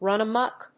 アクセントrùn amók